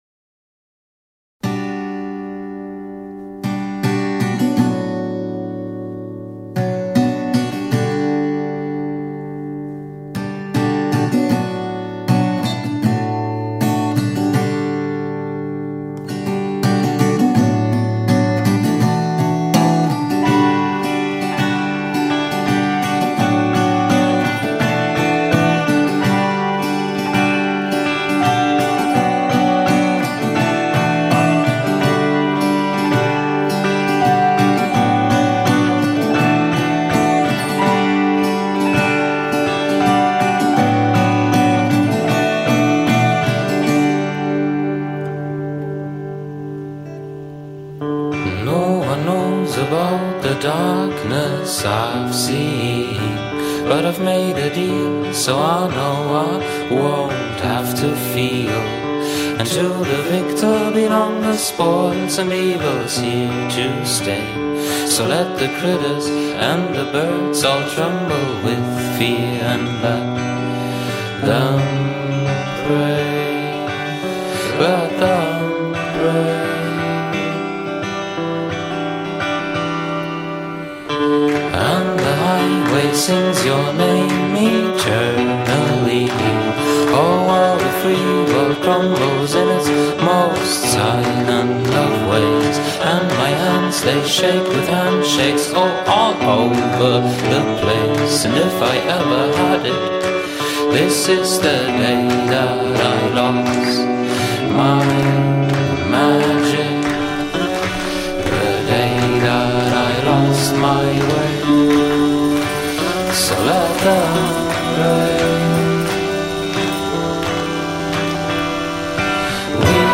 Anti-folk duo